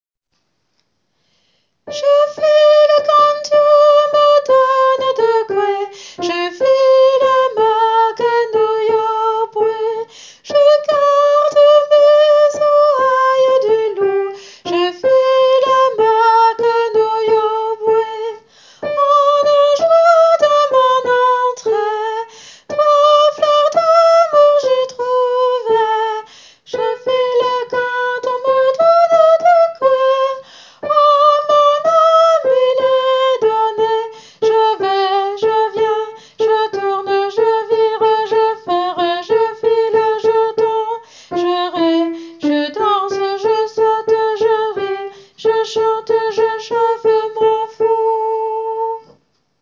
[private role=”subscriber”][/private]Ténor :
je-file-tenor.wav